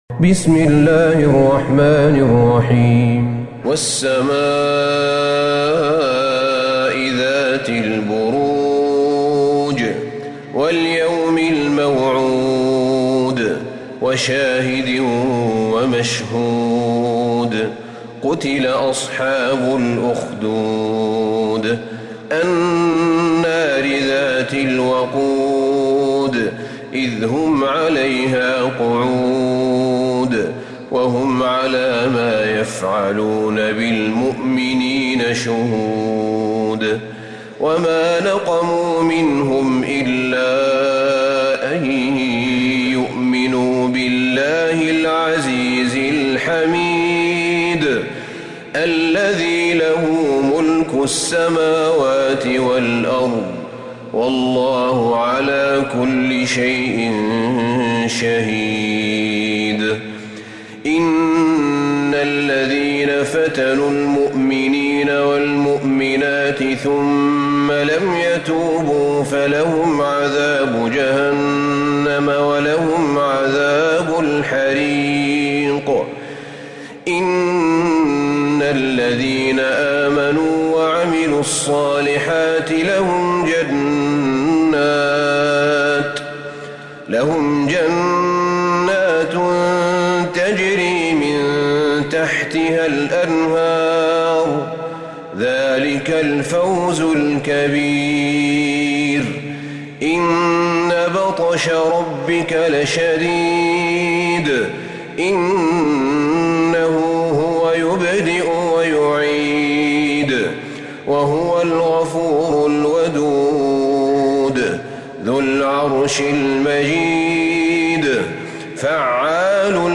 سورة البروج Surat Al-Buruj > مصحف الشيخ أحمد بن طالب بن حميد من الحرم النبوي > المصحف - تلاوات الحرمين